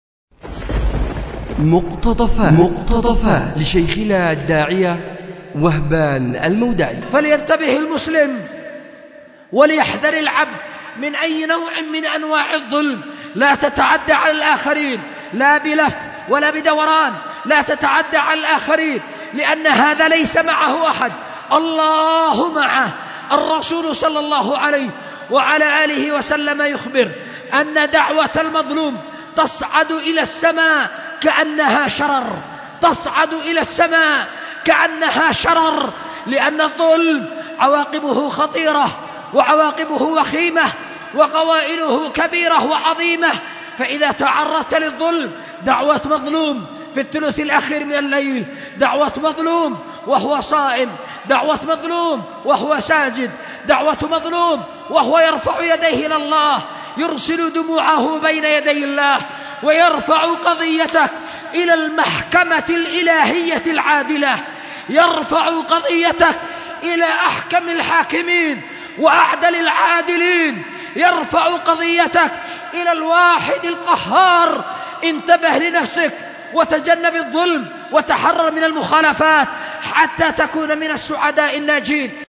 أُلقي بدار الحديث للعلوم الشرعية بمسجد ذي النورين ـ اليمن ـ ذمار